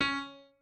pianoadrib1_12.ogg